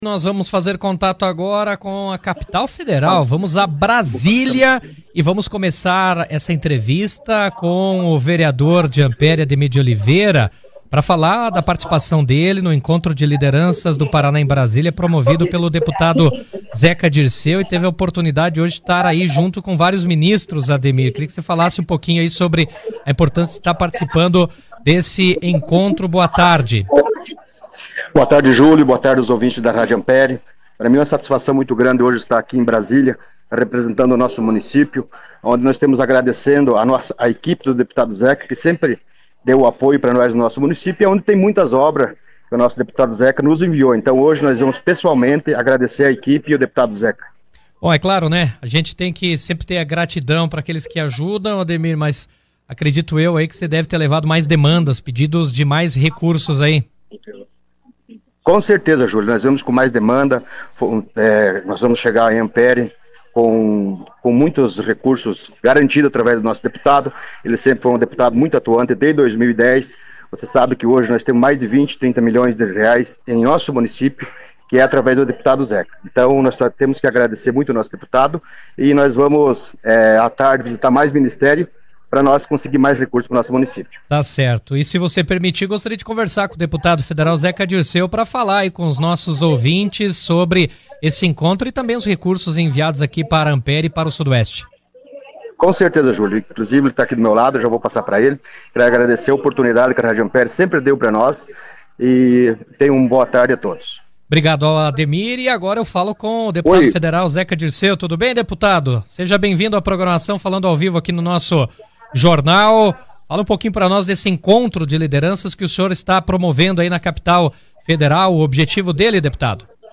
O deputado federal Zeca Dirceu participou ao vivo, por telefone direto de Brasília, do Jornal RA 2ª Edição desta terça-feira, 24, onde comentou o encontro de lideranças paranaenses realizado na Capital Federal.